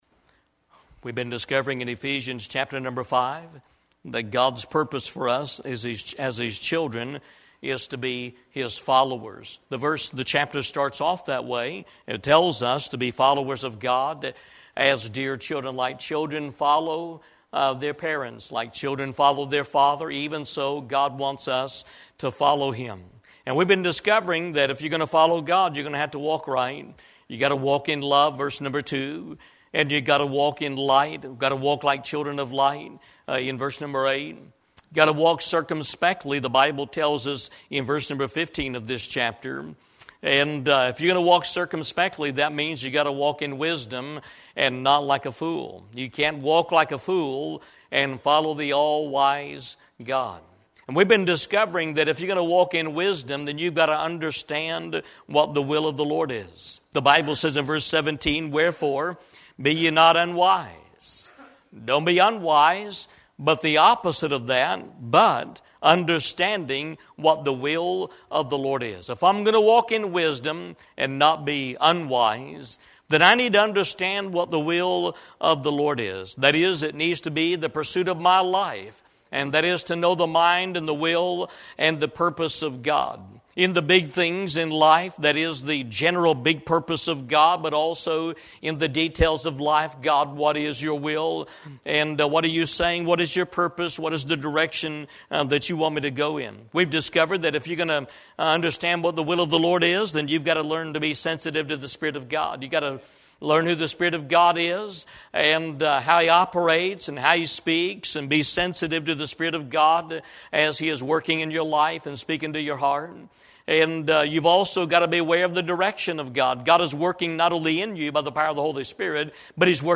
Eastern Gate Baptist Church - Understanding What The Will Of The Lord Is 9